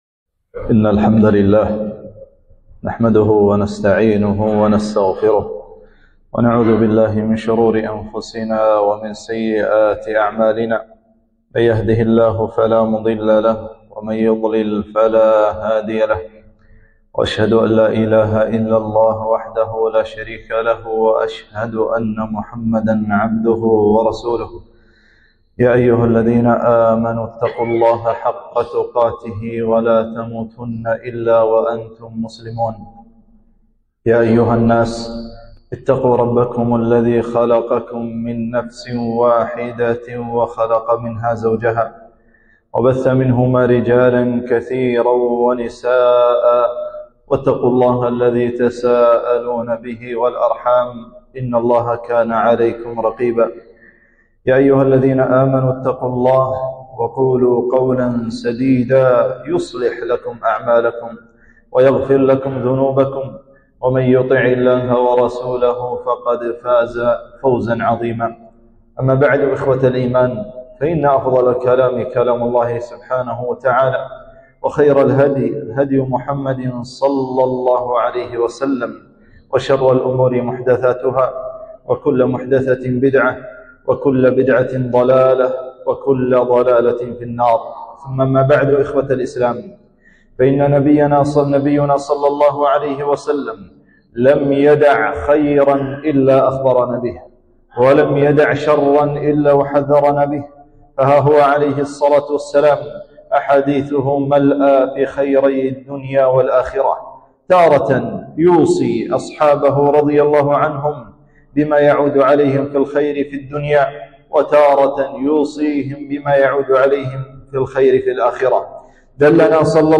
خطبة - من وصايا النبي صلى الله عليه وسلم